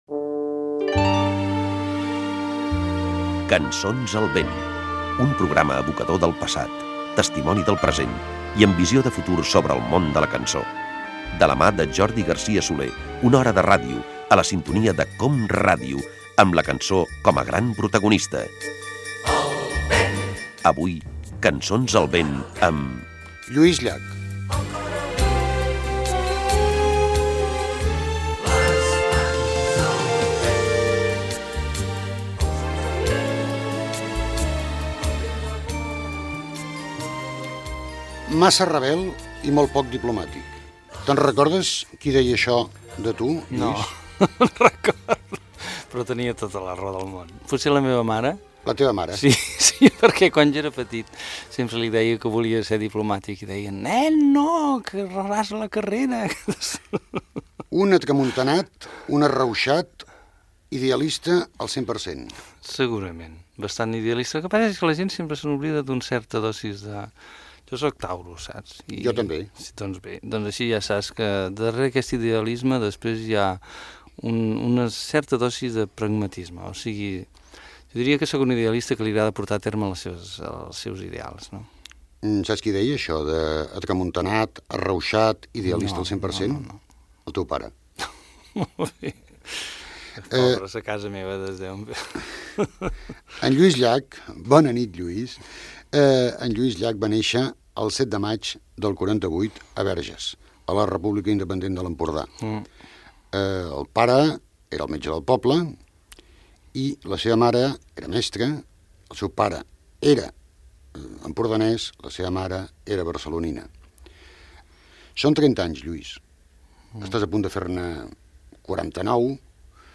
Careta del programa, presentació i entrevista al cantautor Lluís Llach.
FM